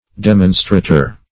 Demonstrater \Dem"on*stra`ter\, n.